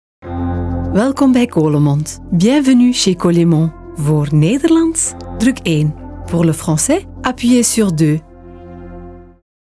Diep, Natuurlijk, Vertrouwd, Vriendelijk, Warm
Telefonie